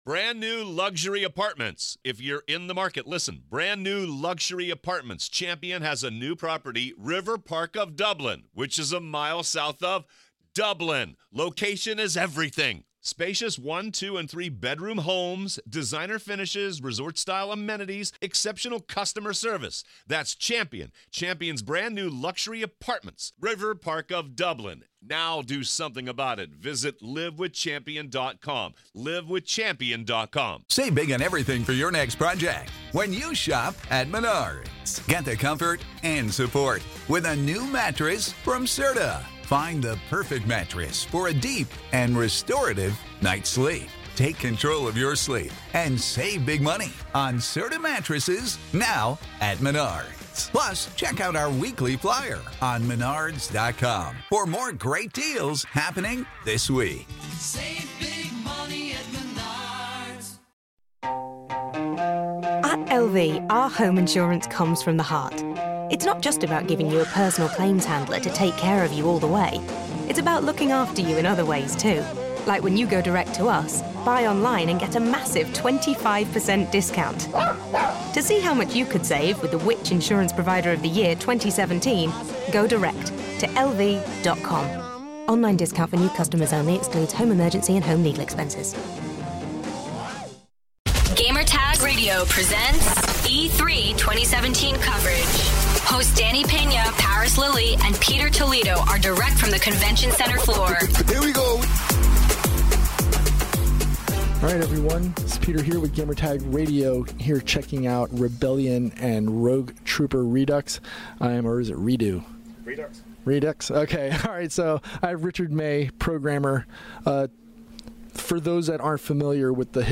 E3 2017: Rogue Trooper Redux Interview